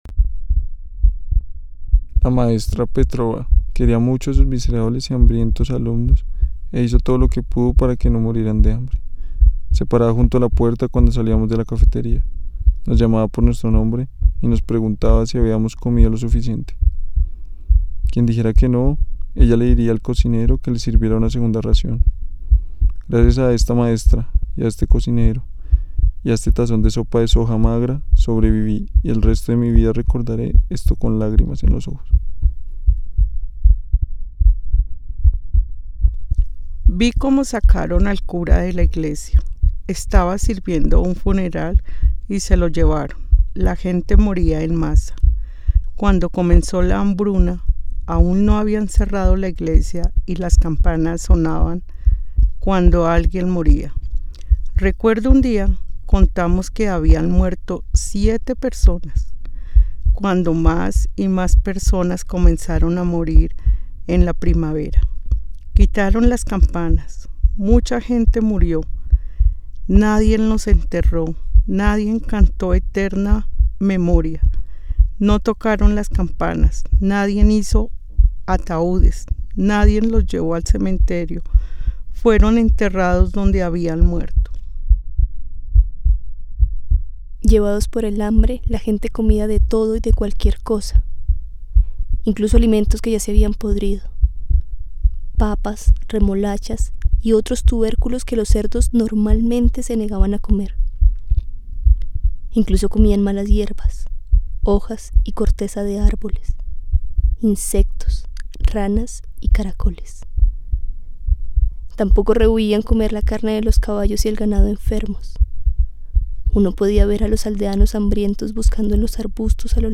Instalación sonora interactiva – Investigación-creación
Sistema de transducción sonora, una carreta de madera, voces, paisaje sonoro, memoria oral